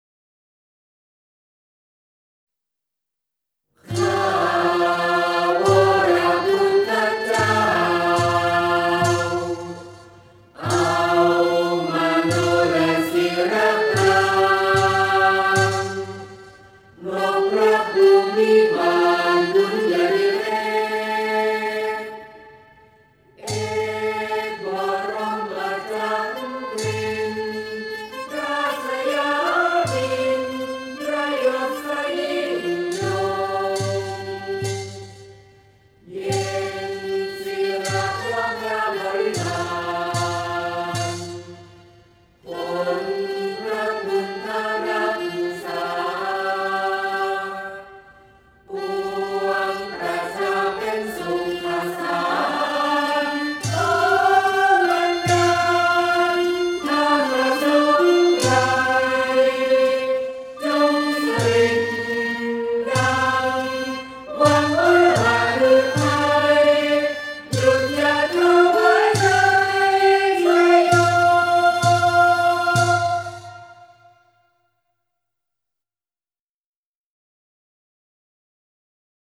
เพลงสรรเสริญพระบารมี (วงเครื่องสาย)